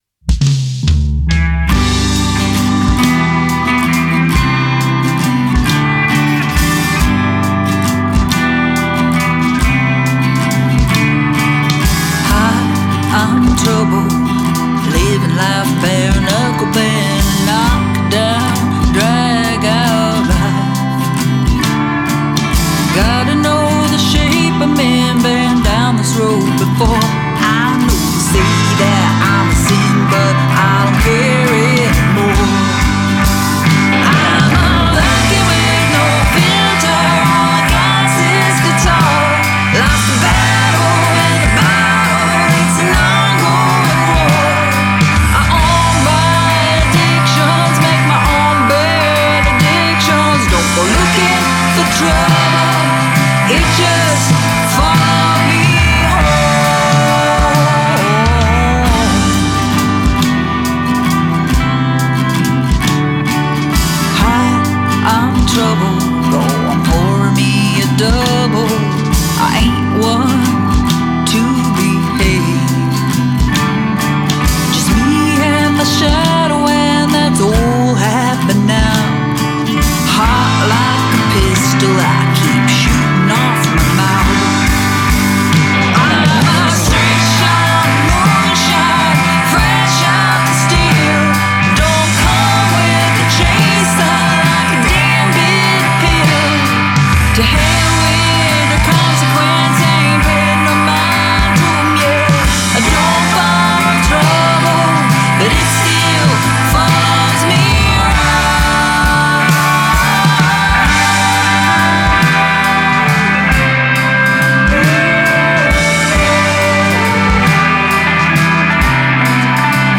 Key: G | BPM: 130, 3/4 time